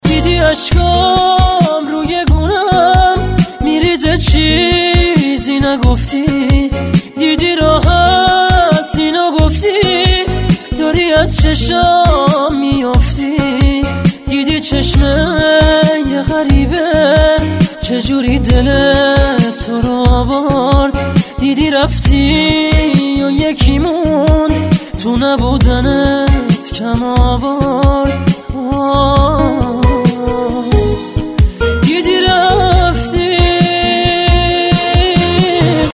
رینگتون احساسی (با کلام)